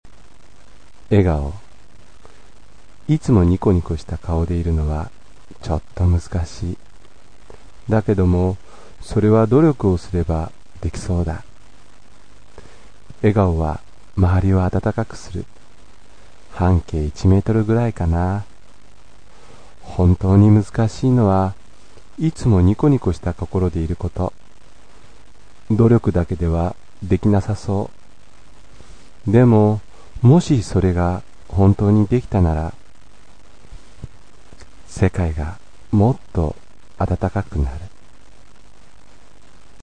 形式…口語自由詩
朗読